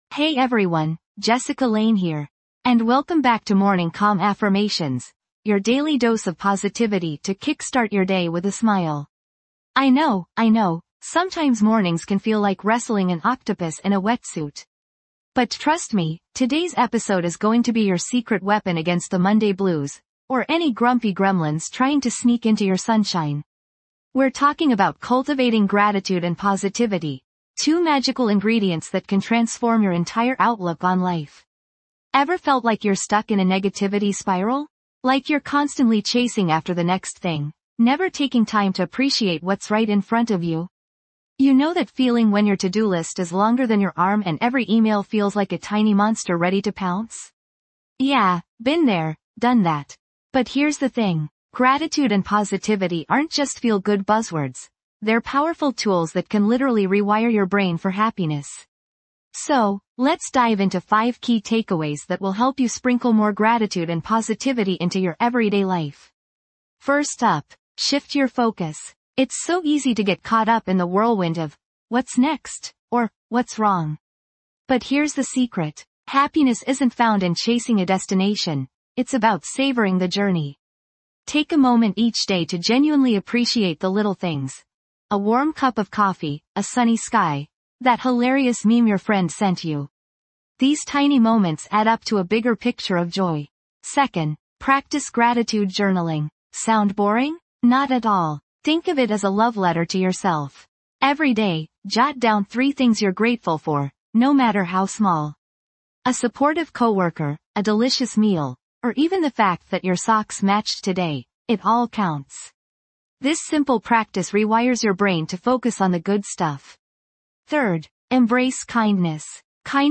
"Morning Calm Affirmations | Start the Day Grounded" offers soothing guided meditations and powerful affirmations designed to cultivate inner stillness and tranquility. Each morning episode provides a few minutes of quiet reflection, helping you release stress, quiet racing thoughts, and ground yourself in the present moment.